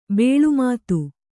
♪ bēḷu mātu